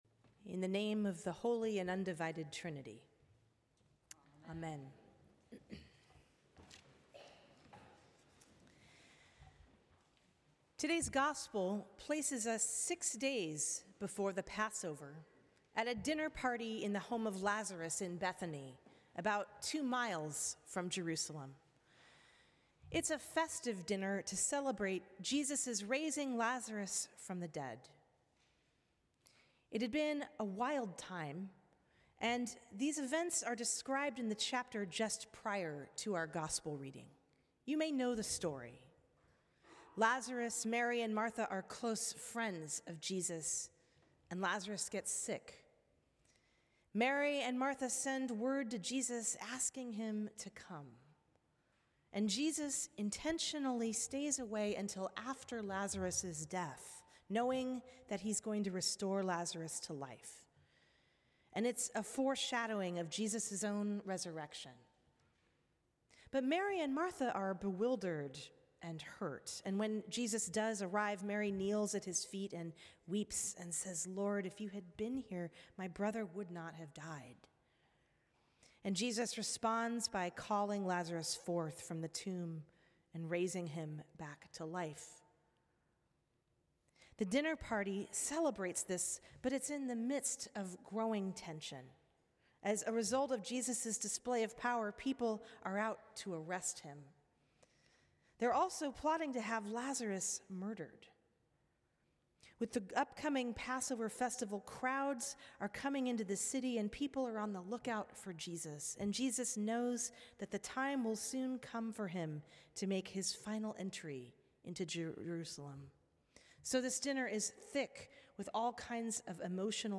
Sermons from St. Cross Episcopal Church Fifth Sunday in Lent Apr 06 2025 | 00:10:01 1x 00:00 / 00:10:01 Subscribe Share Apple Podcasts Spotify Overcast RSS Feed Share Link Embed